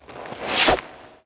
rogue_skill_slowing_slice.wav